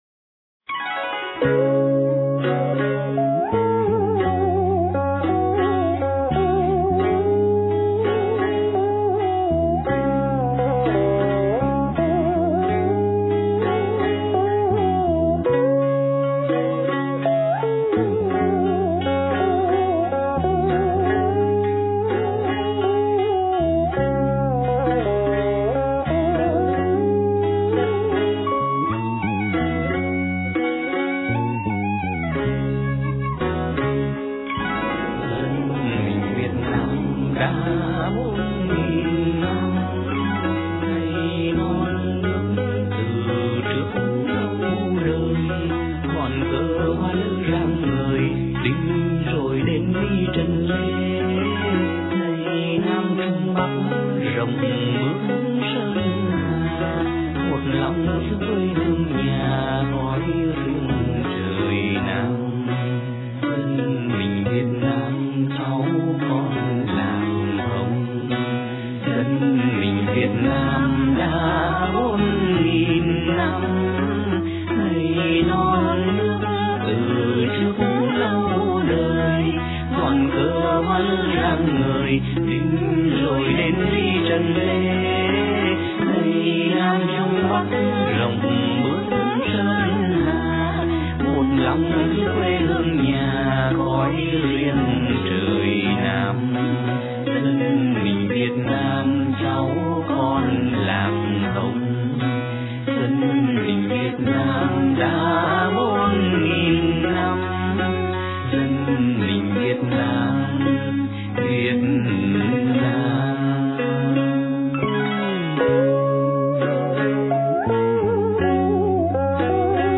Lo-Fi  mp3  format-